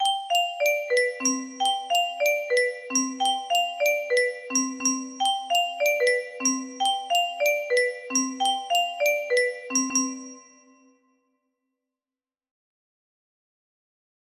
Kind of demo tho